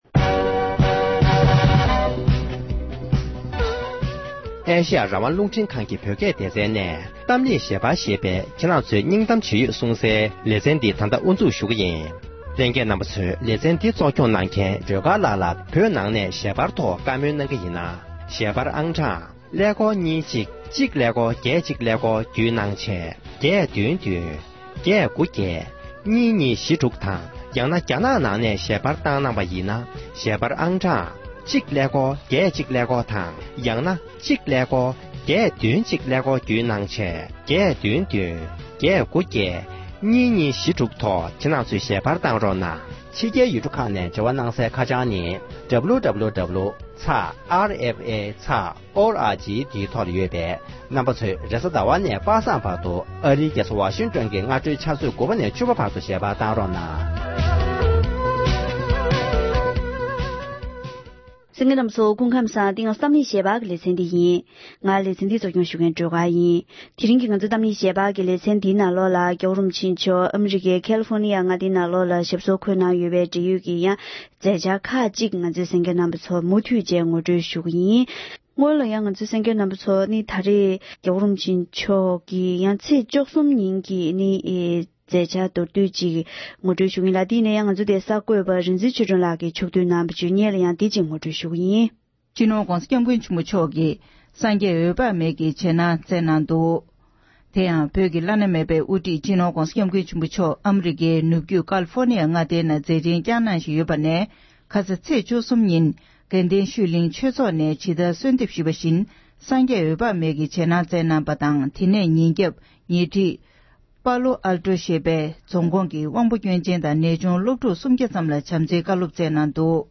༄༅༎དེ་རིང་གི་གཏམ་གླེང་ཞལ་པར་ཞེས་པའི་ལེ་ཚན་ནང་དུ།